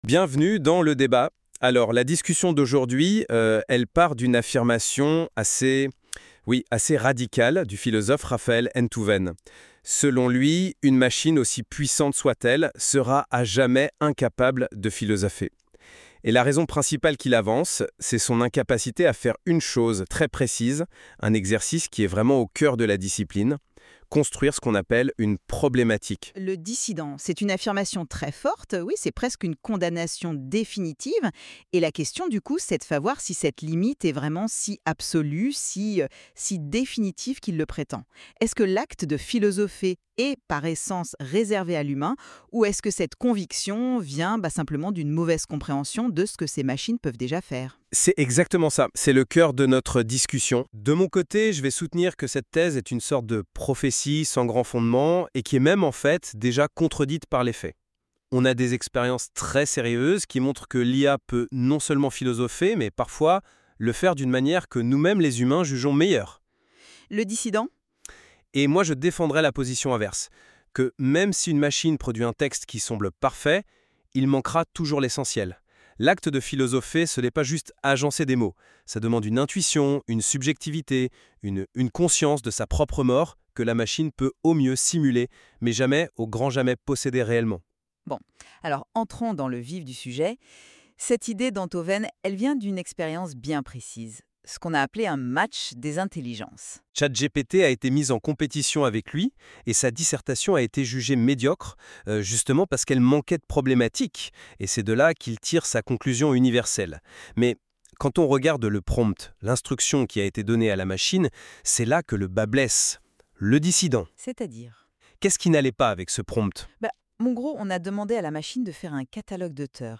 [!Success] Ecoutez le débat contradictoire /uploads/default/original/2X/c/c06447d928ed02ef5cf6d68bf738fa2081d0ca3d.mp3